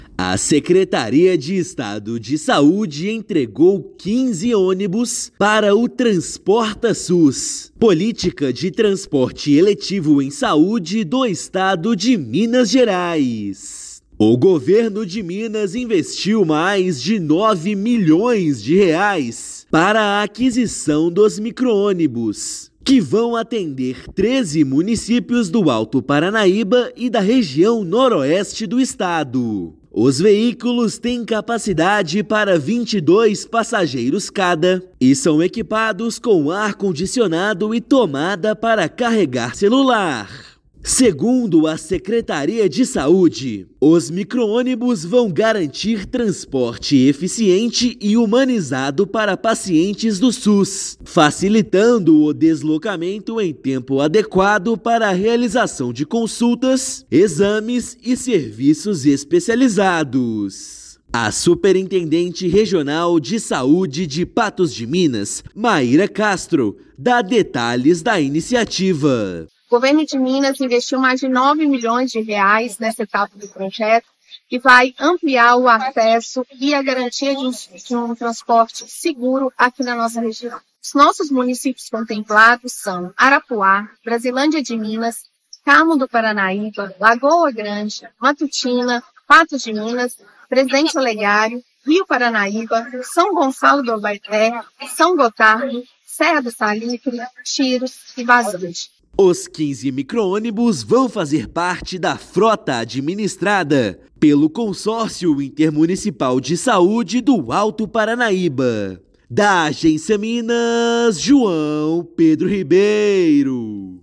Foram investidos mais de R$ 9 milhões para o serviço de saúde que beneficiará a população em 13 municípios. Ouça matéria de rádio.